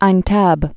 (īn-tăb)